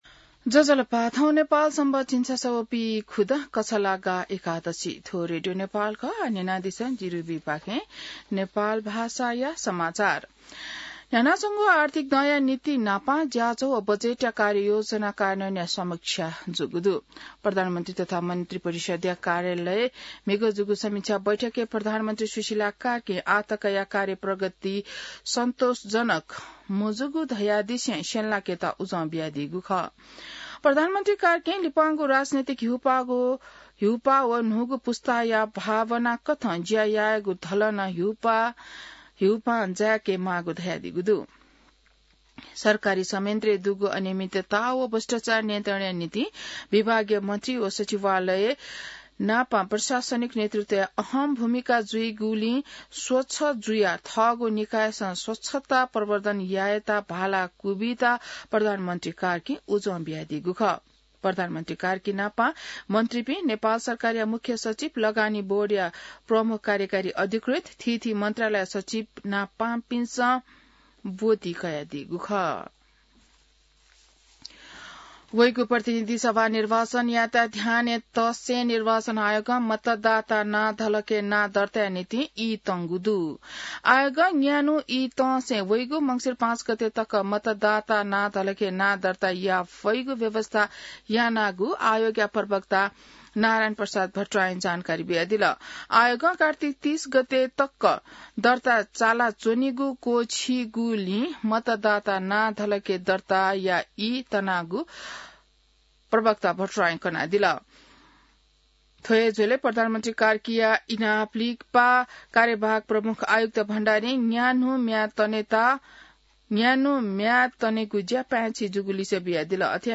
नेपाल भाषामा समाचार : २९ कार्तिक , २०८२